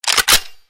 boltpull.mp3